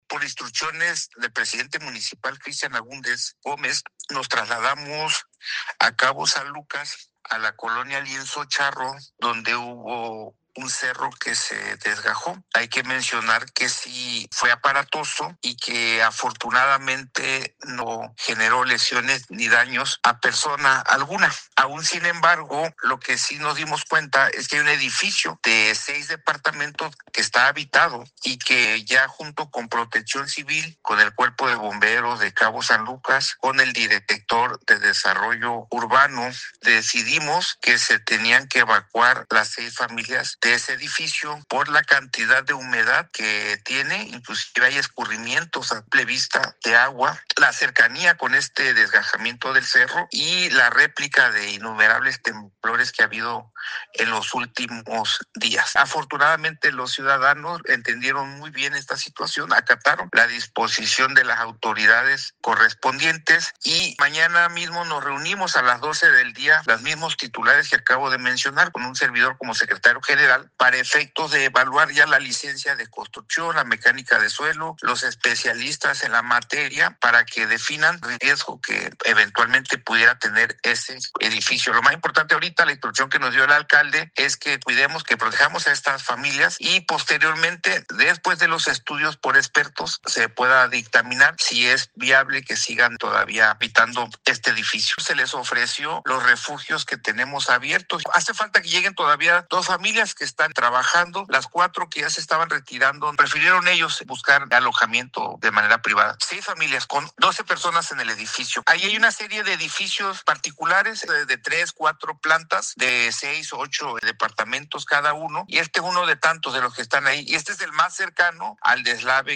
Alberto Rentería Santana – secretario general del XV Ayuntamiento de Los Cabos